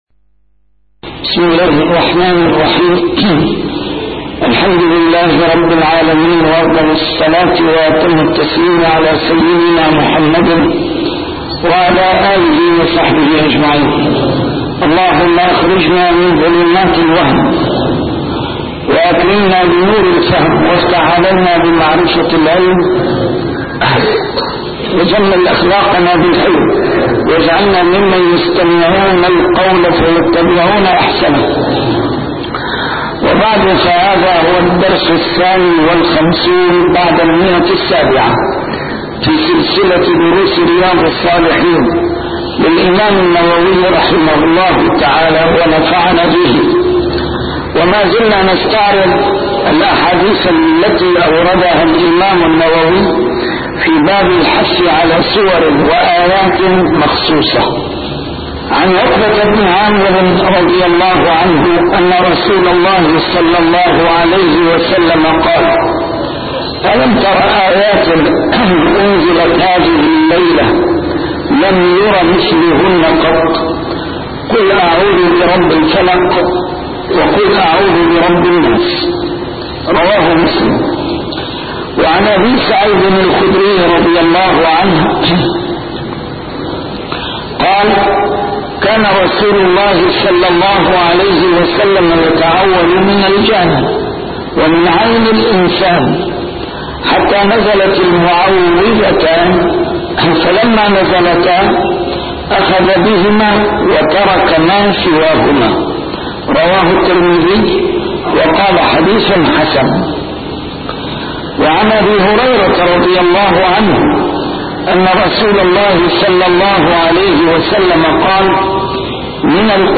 A MARTYR SCHOLAR: IMAM MUHAMMAD SAEED RAMADAN AL-BOUTI - الدروس العلمية - شرح كتاب رياض الصالحين - 752- شرح رياض الصالحين: الحث على سور وآيات مخصوصة